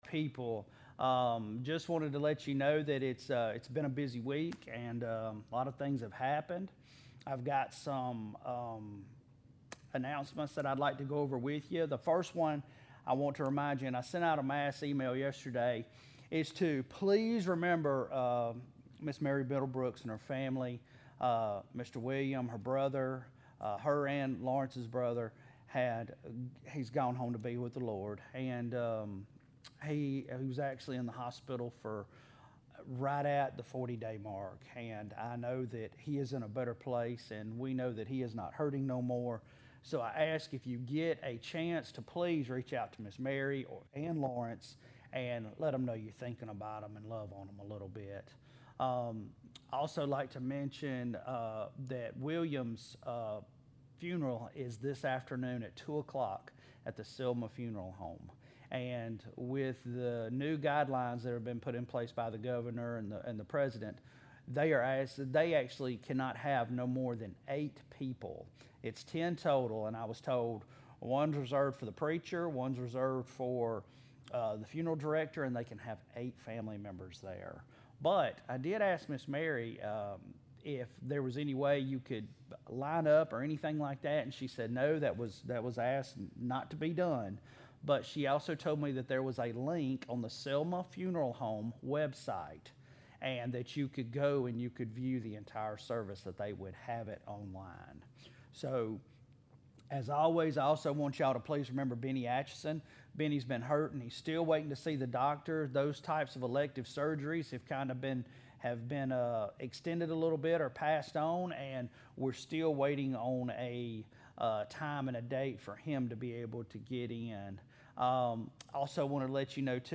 Autaugaville Baptist Church Sermons
Palm-Sunday-Message.mp3